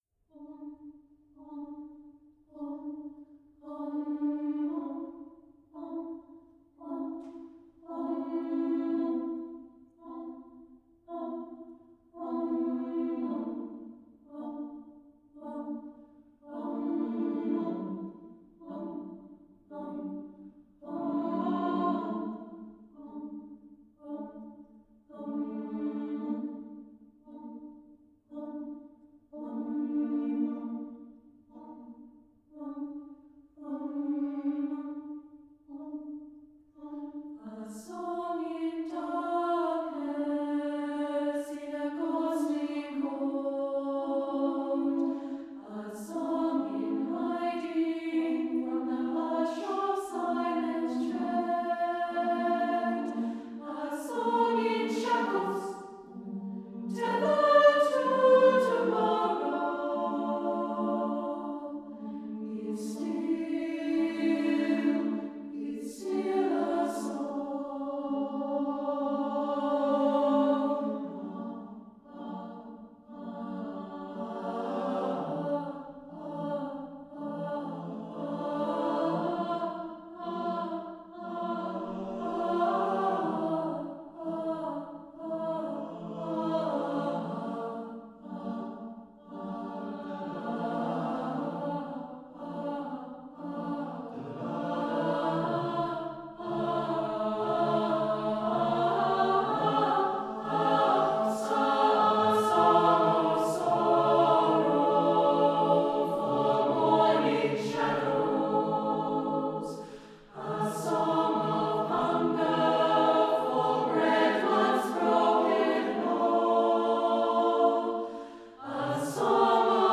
Live Demo